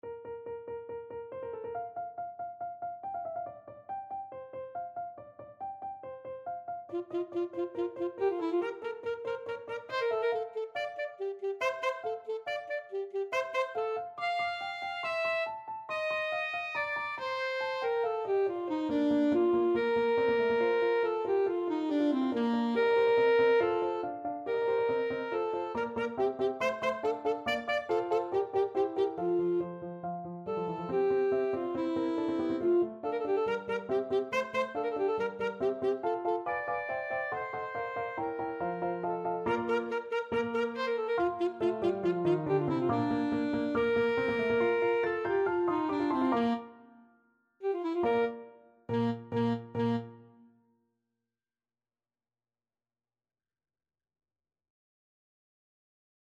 Free Sheet music for Alto Saxophone
= 140 Allegro (View more music marked Allegro)
Bb major (Sounding Pitch) G major (Alto Saxophone in Eb) (View more Bb major Music for Saxophone )
Classical (View more Classical Saxophone Music)
MagicFluteOverture_ASAX.mp3